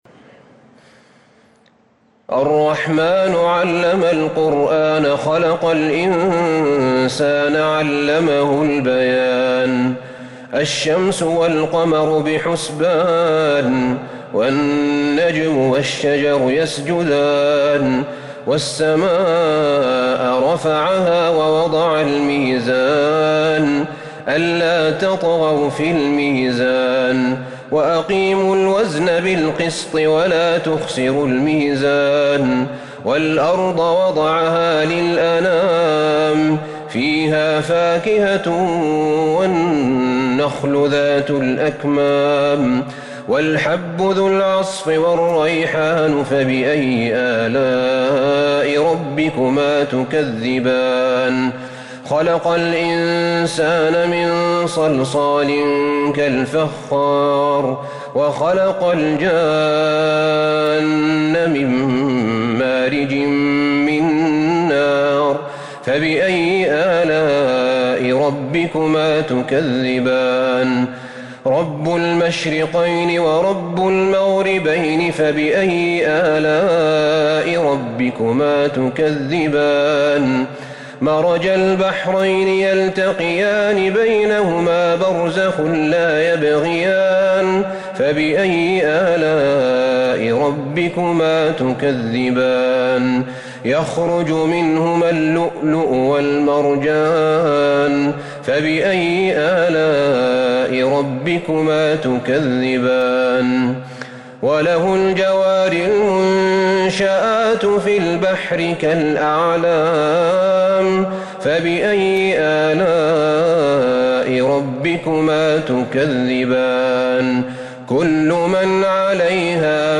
سورة الرحمن Surat Ar-Rahman من تراويح المسجد النبوي 1442هـ > مصحف تراويح الحرم النبوي عام 1442هـ > المصحف - تلاوات الحرمين